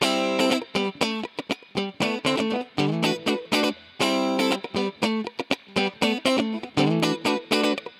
23 Guitar PT3.wav